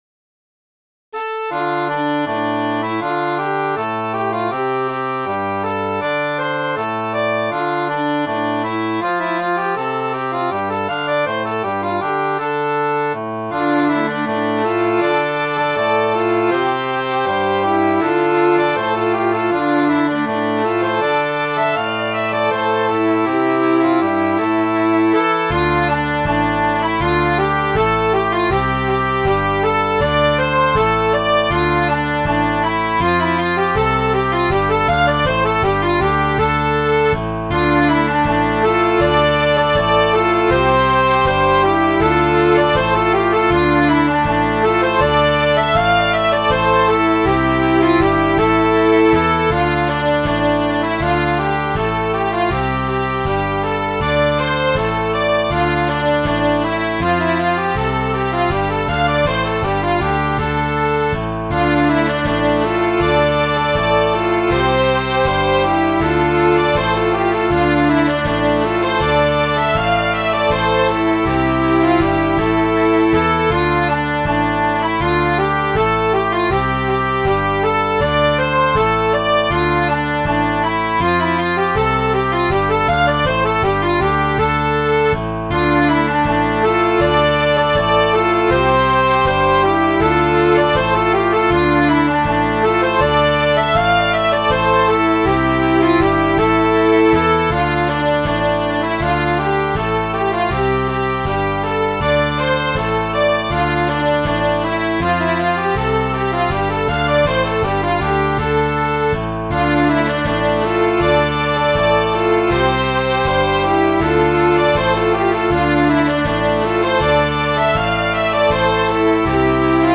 coldrain.mid.ogg